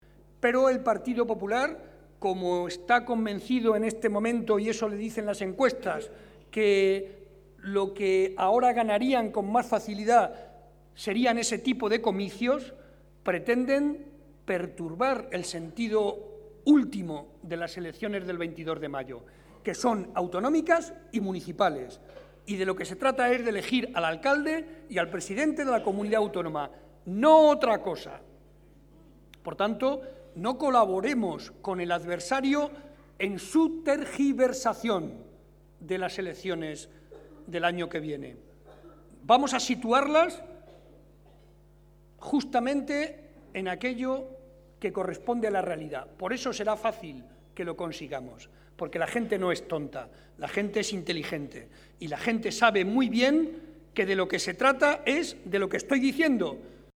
Estas son algunas de las manifestaciones que ha hecho esta noche el secretario regional del PSOE y presidente de Castilla-La Mancha, José María Barreda, durante el transcurso de la tradicional cena de Navidad del PSOE de Guadalajara y que ha congregado a más de 400 afiliados y simpatizantes.